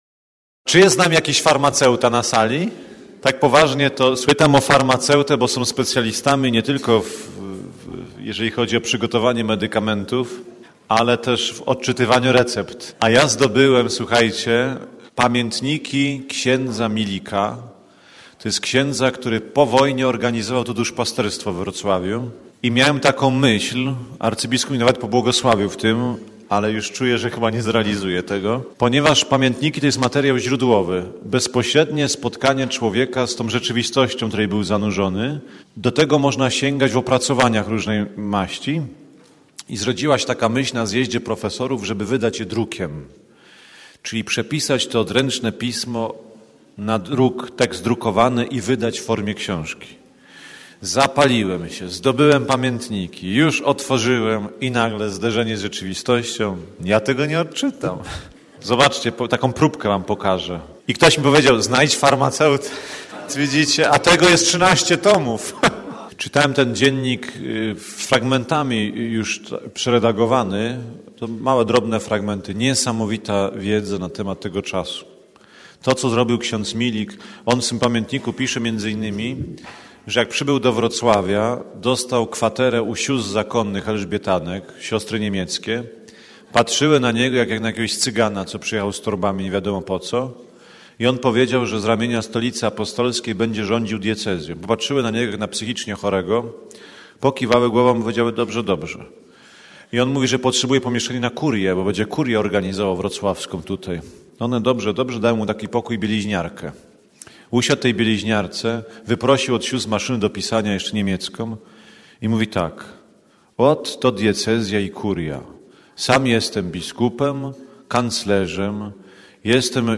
Zapraszamy Państwa do wysłuchania wykładów z tego dnia.